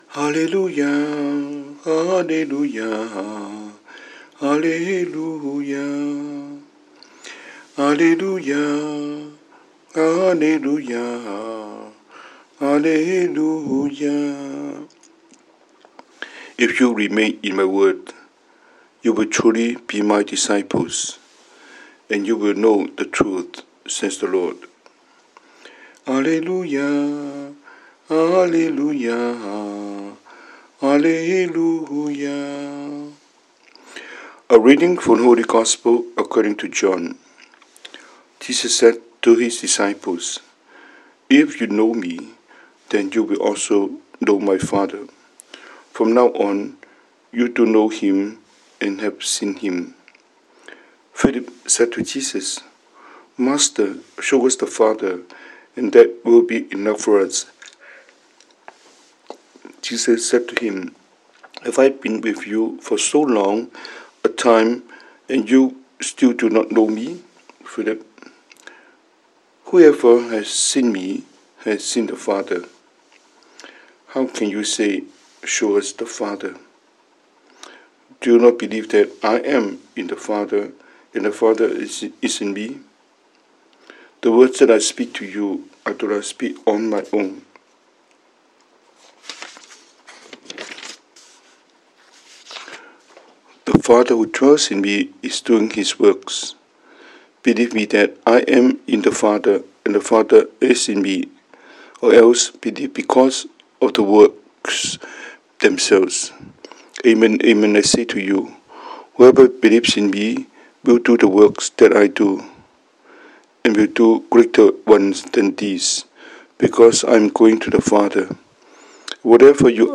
中文講道,